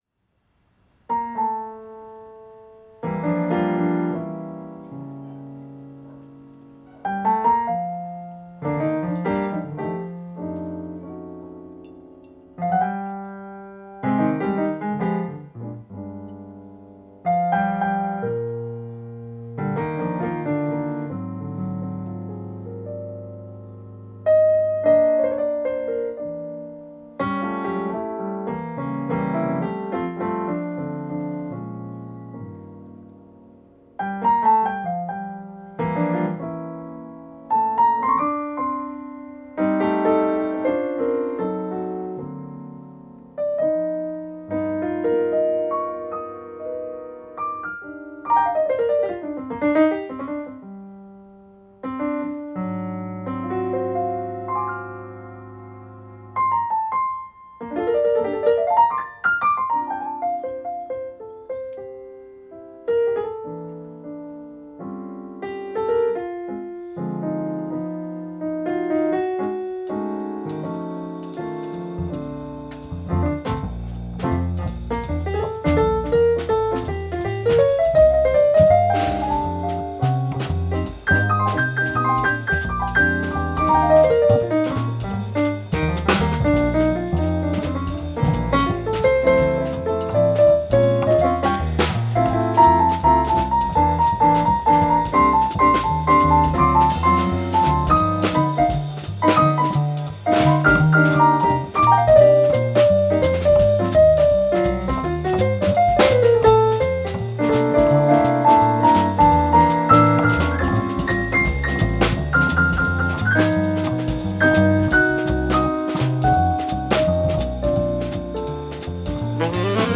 quartet